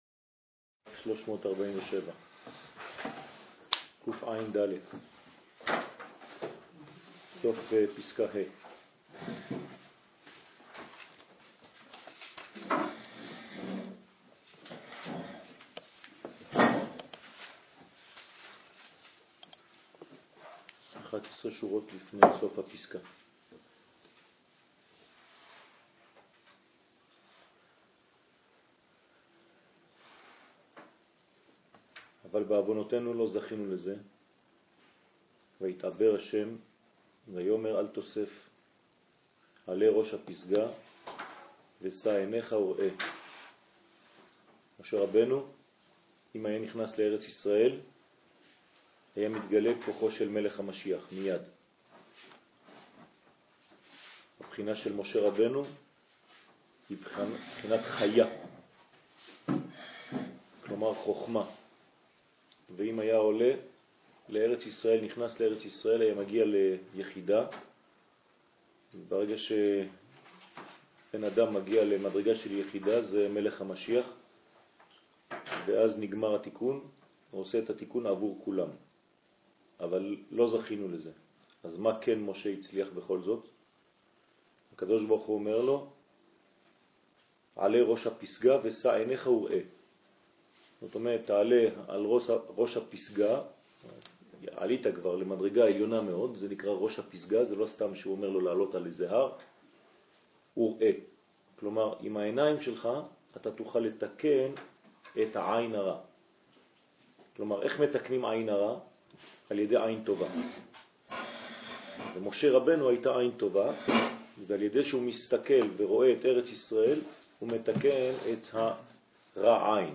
שיעור בוקר : עינין הטבת חלום